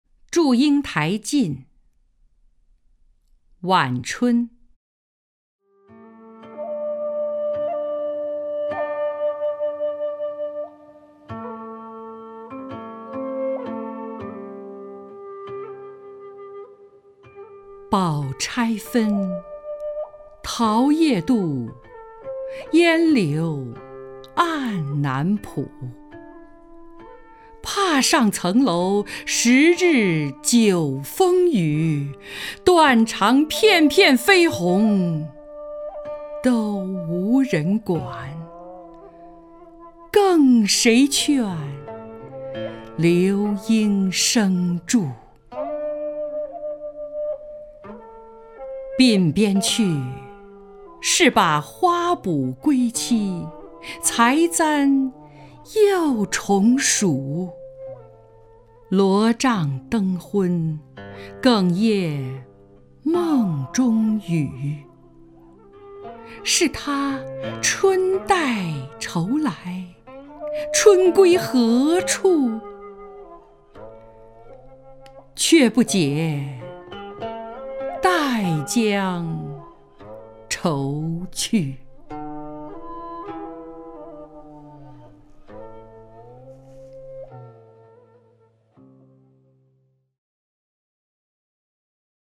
张筠英朗诵：《祝英台近·晚春》(（南宋）辛弃疾)
名家朗诵欣赏 张筠英 目录